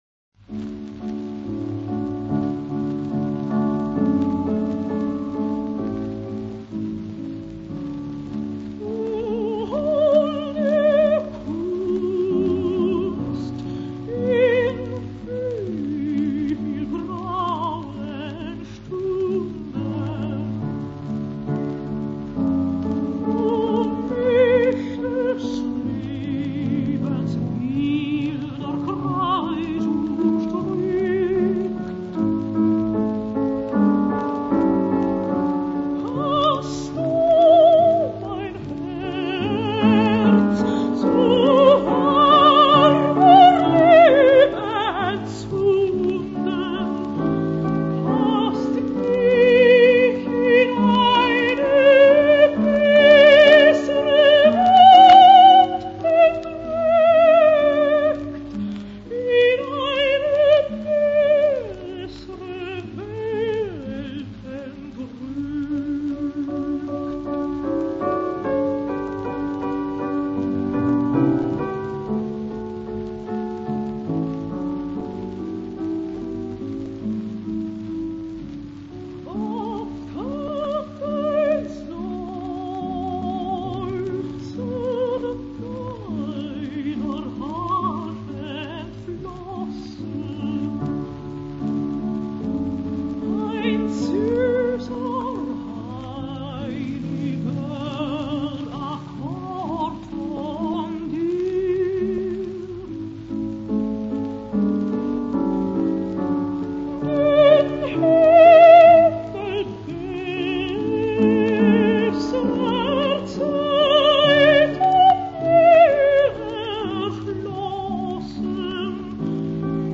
Легкий, изумительно красивого, серебристого тембра голос Шварцкопф обладал удивительной способностью перекрывать любую толщу оркестровых масс.
Певица Элизабет Шварцкопф, обладательница одного из самых выдающихся сопрано XX века, скончалась в своем доме в Австрии на 91-м году жизни.